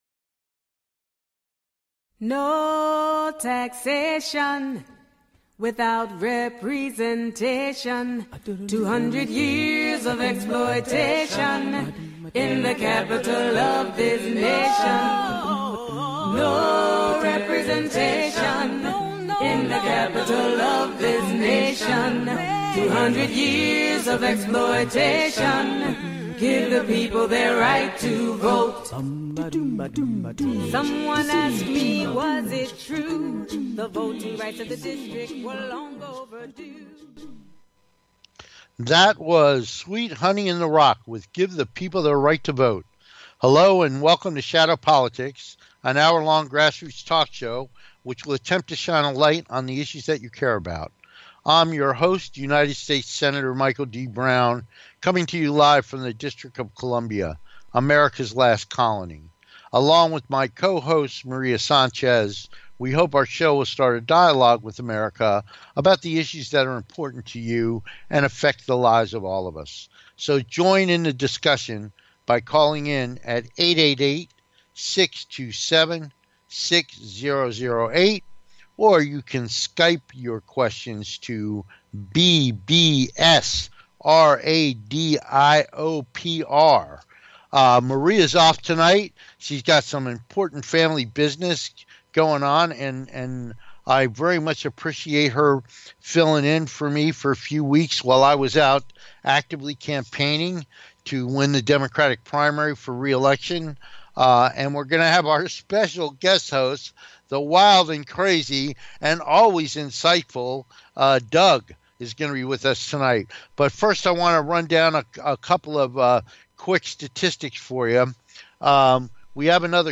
Senator Michael D. Brown is back with us after his campaign win this past week! We'll be discussing current events, activities in Washington and President Trump’s 'Zero Tolerance' immigration policy that has led to images of children screaming as they were separated from their mothers.
Shadow Politics is a grass roots talk show giving a voice to the voiceless.